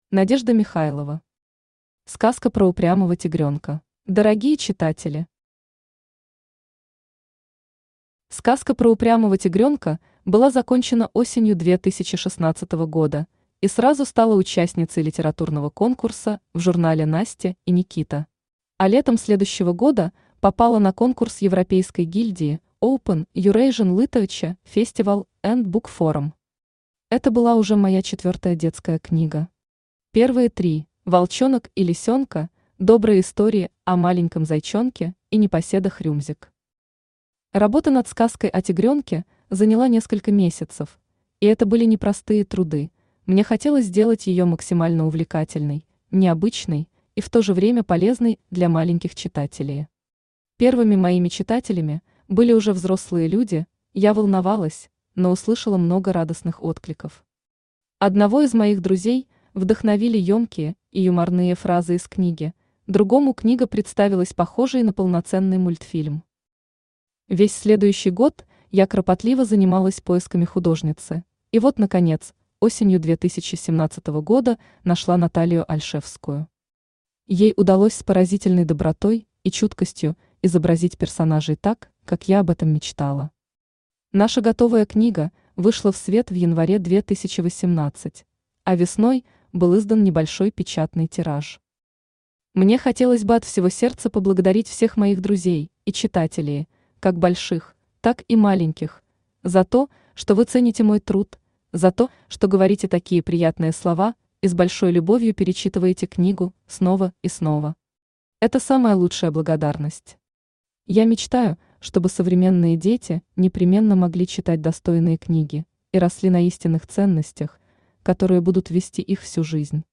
Аудиокнига Сказка про упрямого Тигрёнка | Библиотека аудиокниг
Aудиокнига Сказка про упрямого Тигрёнка Автор Надежда Александровна Михайлова Читает аудиокнигу Авточтец ЛитРес.